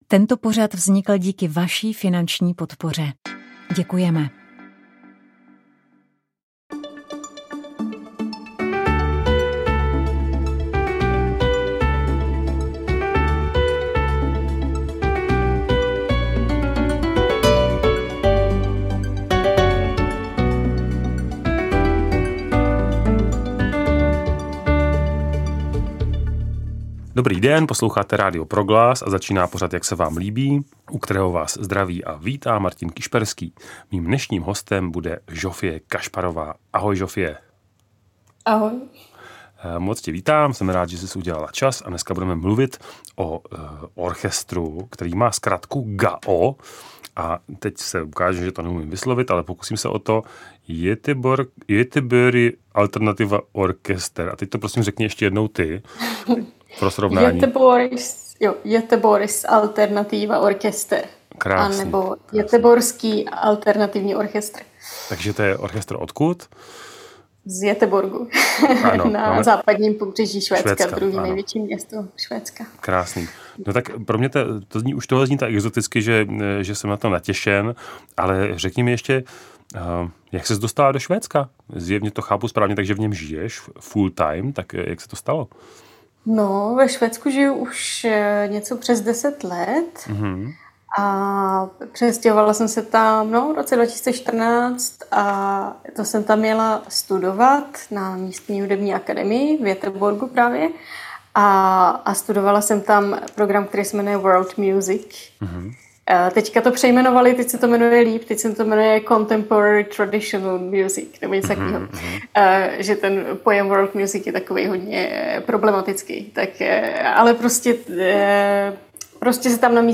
Repertoár tria se zakládá převážně na venkovských písních, které jsou interpretované co nejvíc autenticky, vedle čistě vokálních vystoupení ale Rodjenice příležitostně spolupracují i s dalšími umělci a snaží se lidovou hudbu konfrontovat také s experimentální a soudobou komponovanou hudbou.
Rozhovor se srbskou skupinou Rodjenice